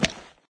icegrass2.ogg